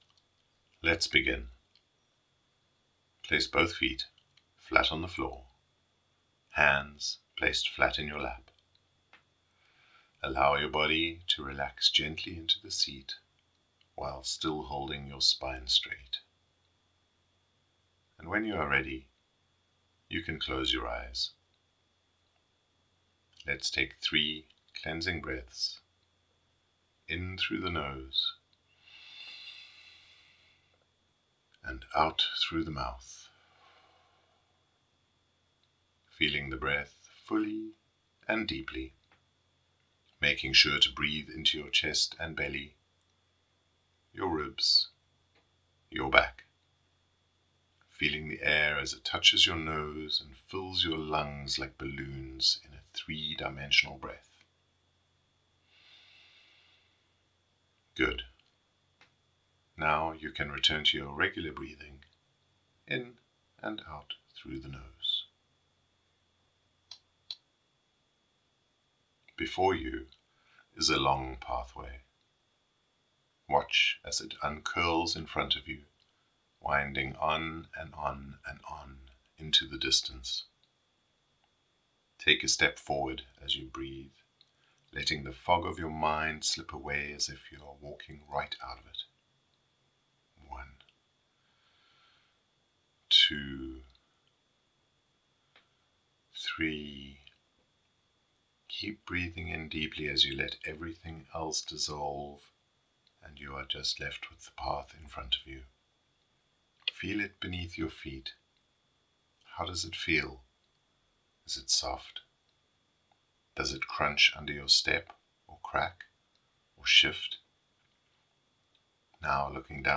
Guided Meditation inner landscape
WS01-meditation-Zentangles2.mp3